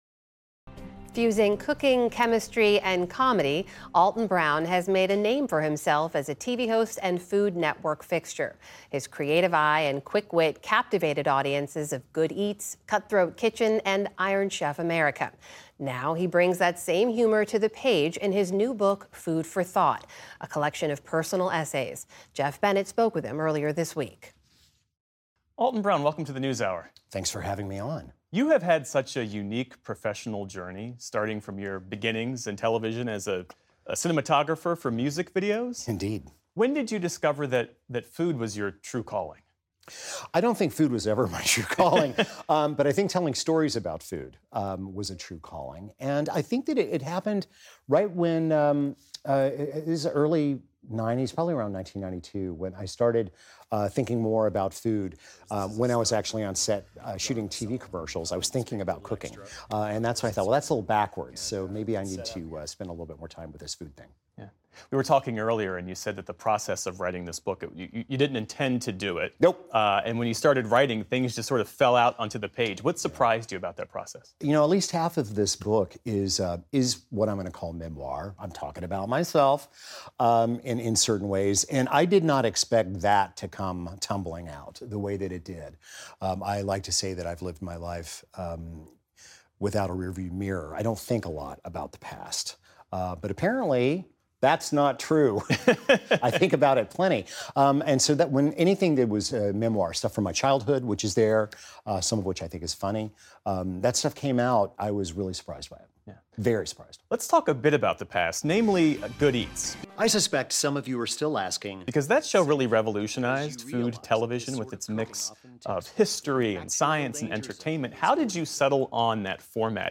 Now, he brings that same humor to the page in his new book, “Food for Thought.” Geoff Bennett sat down with Brown to dis…